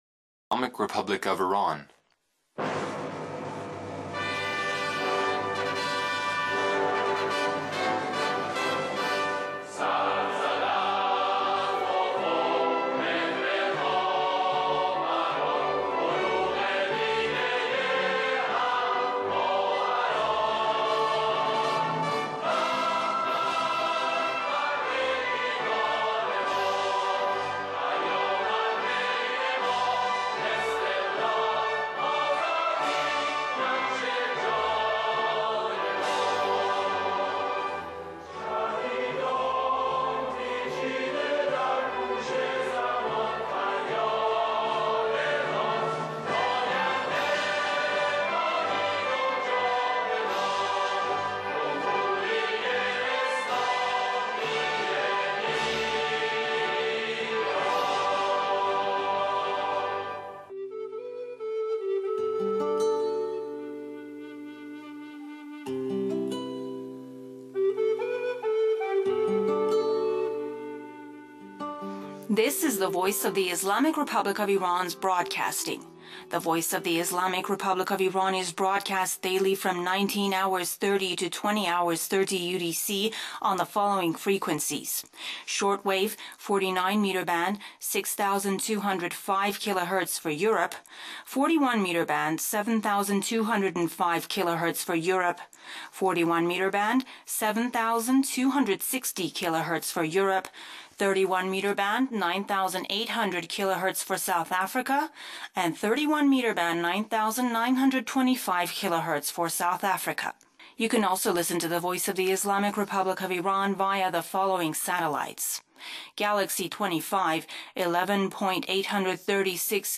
News from Tehran on October 21, 2008 – not an earth shattering day as far as events in the world are concerned.
Aside from that mostly local news, sprinkled with international news, including interviews with Wall Street Insiders on the latest financial Crisis in America.